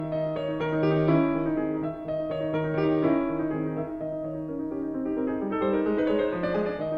First movement – Allegro, F minor, sonata form
It is a musical technique perfected by the Mannheim Orchestra, with a rising figure, speeding up and growing louder.
The theme in character is very agitated, like a steam engine driving forward breathlessly.